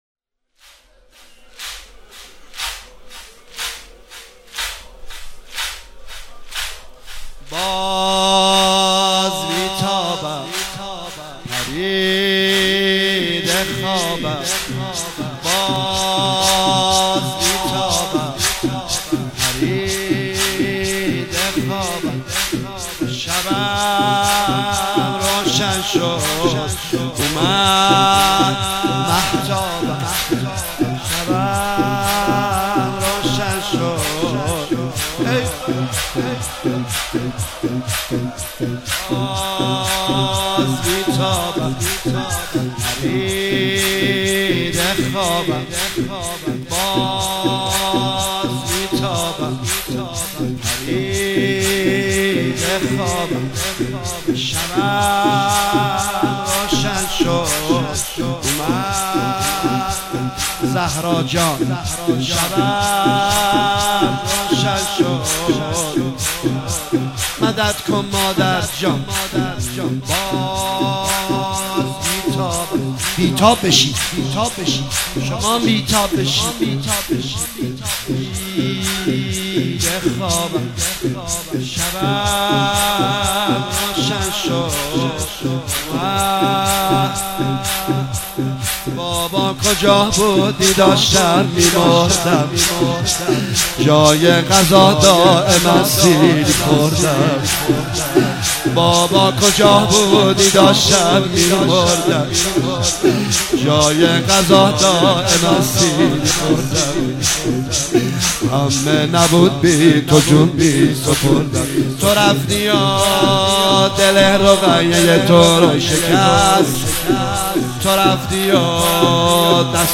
شب سوم محرم 96 - مکتب الحسین - زمینه - باز بی تابم پریده خوابم